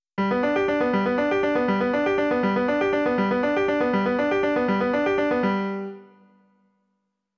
arpeggioPattern = [0, 4, 7, 12, 7, 4]   # arpeggiate a major chord
It plays this sound (if you type G3 and 7 as inputs):
arpeggiator2-g3.wav